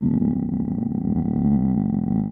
Звуки урчания в животе
На этой странице собраны натуральные звуки урчания в животе, которые можно скачать или слушать онлайн.
Разные вариации звуков: от легкого бурчания до интенсивного урчания после еды.
Шум метеоризма в животе